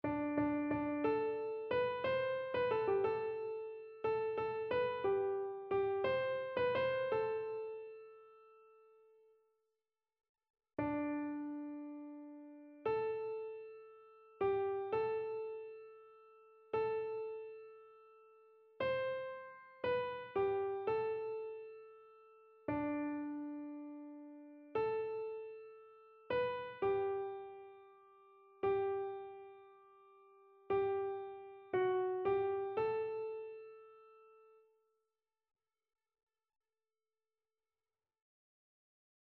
Chœur
SopranoAlto
annee-b-temps-ordinaire-saint-sacrement-psaume-115-soprano.mp3